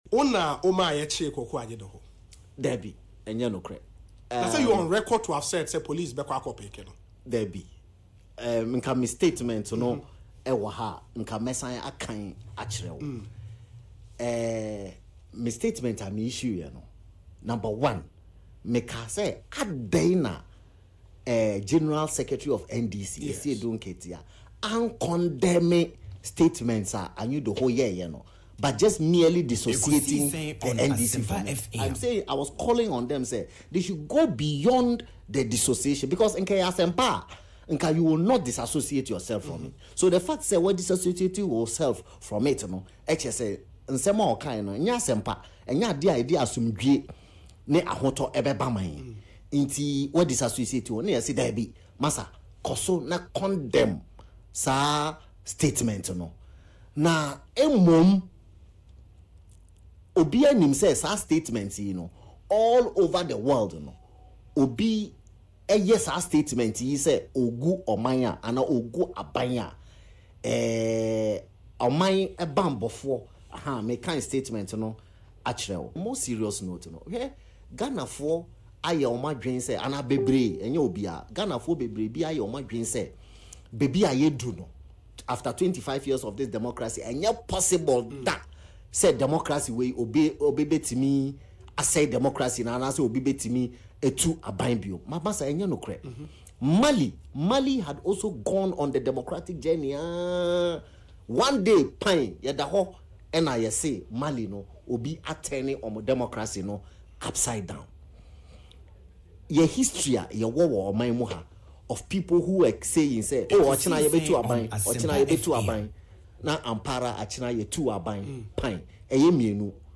But speaking in an exclusive interview on Asempa FM’s Ekosii Sen Wednesday, Mustapha Hamid said Anyidoho’s arrest was not an agenda to divert attention from the US-Ghana military deal.